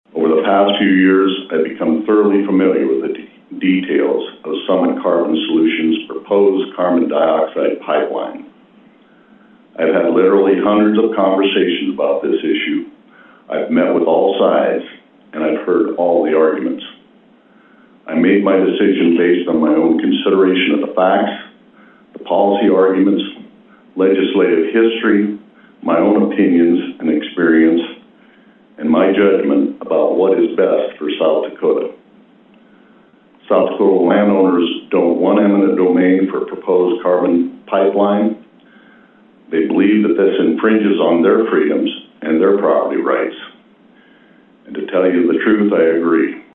Governor Rhoden comments following the signing of HB1052